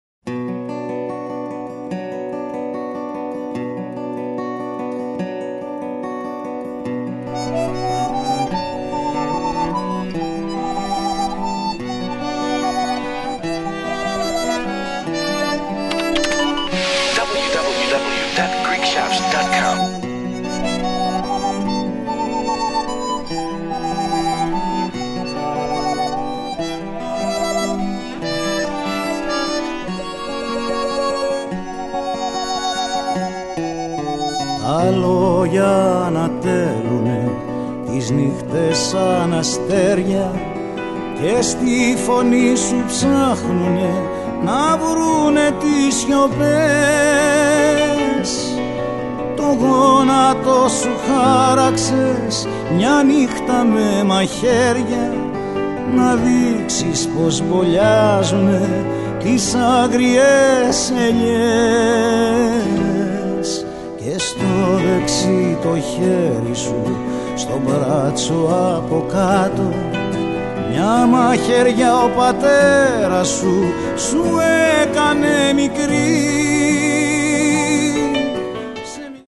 forcefulness melodies and rhythms